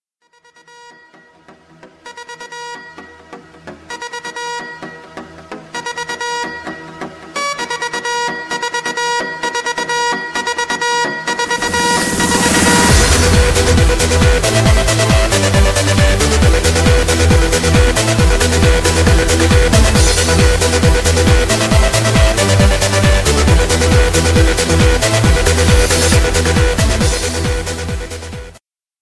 Effets Sonores